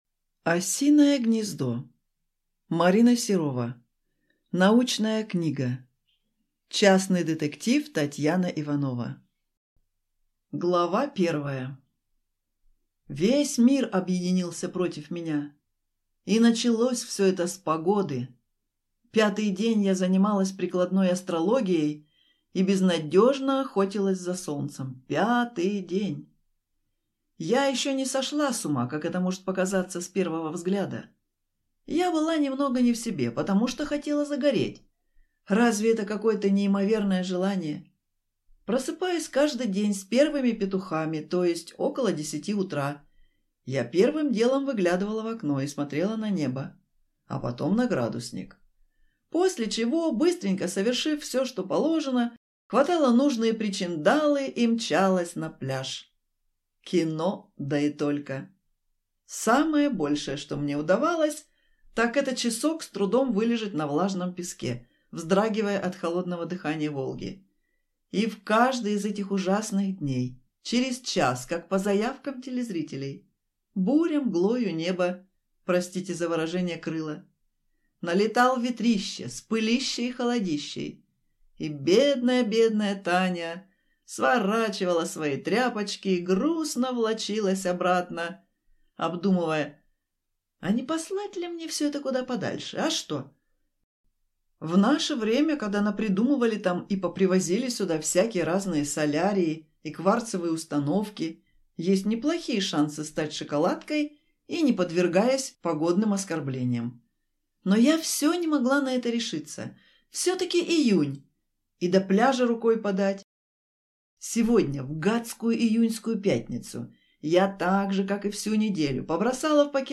Аудиокнига Осиное гнездо | Библиотека аудиокниг